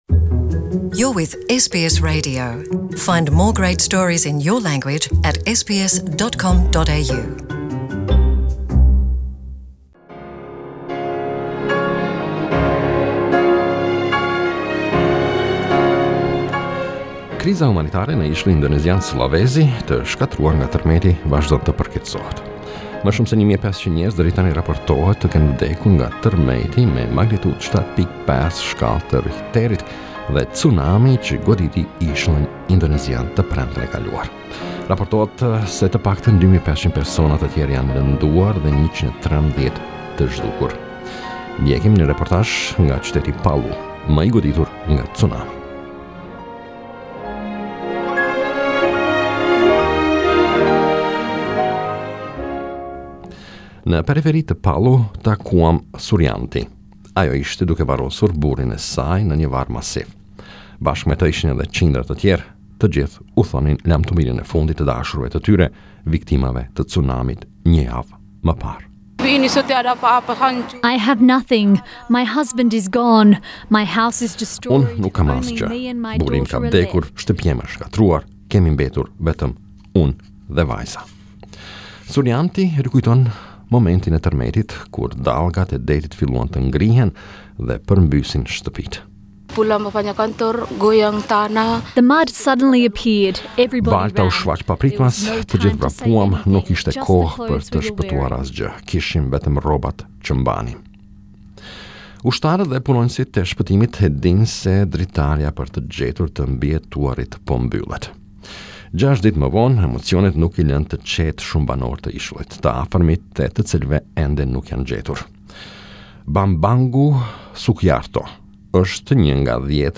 The death toll from the tsunami that hit the Indonesian island of Sulawesi ((sool-ah-WAY-see)) has surpassed 800 people. Hundreds more have been seriously injured, and many remain unaccounted for. Australia is offering Indonesia whatever help it requires.